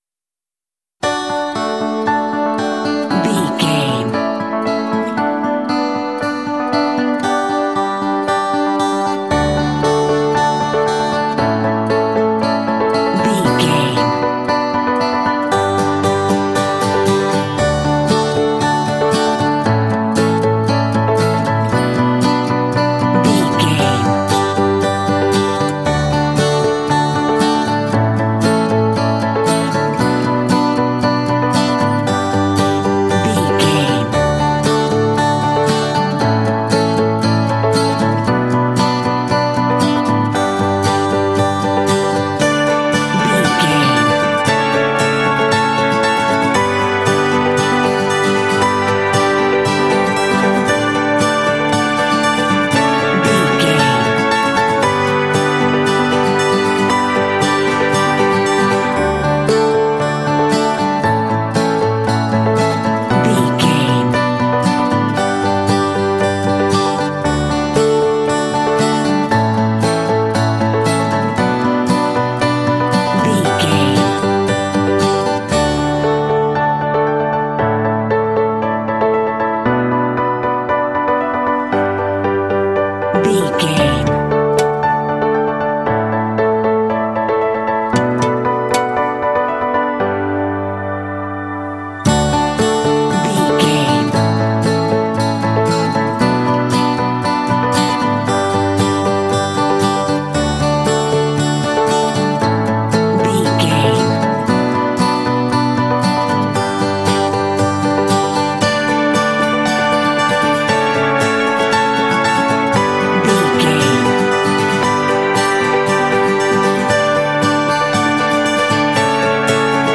Uplifting
Ionian/Major
D
optimistic
happy
piano
acoustic guitar
bass guitar
strings
rock
contemporary underscore
indie